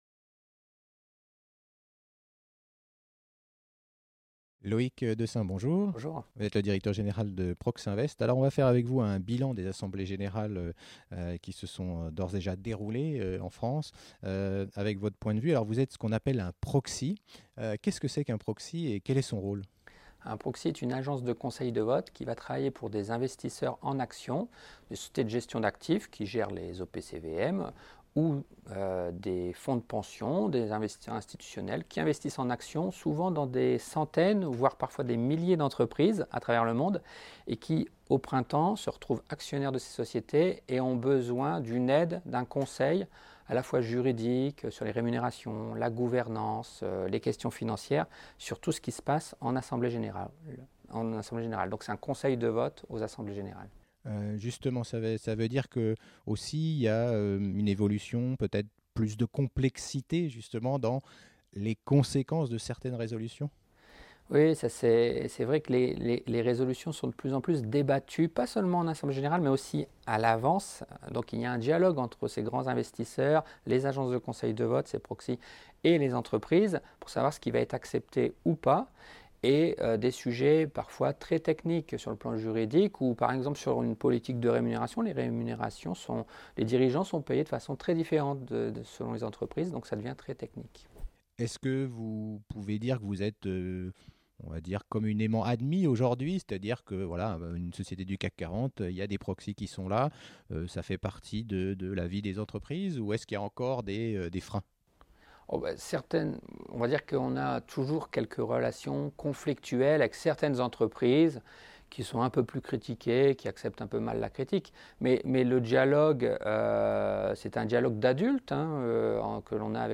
Interview vidéo